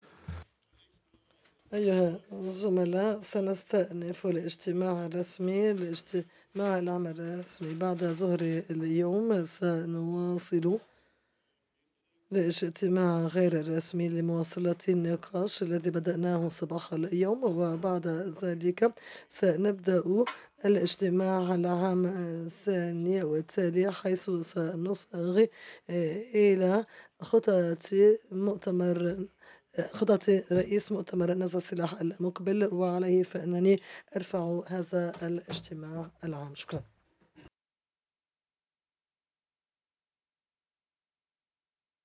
PRESIDENT 12:58:48 0:00:10 00:00:33
Aviso Legal - Interpretación simultánea